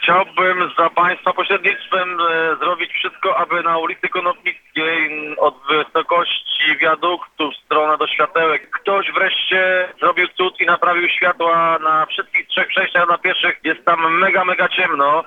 – mówi jeden z naszych słuchaczy.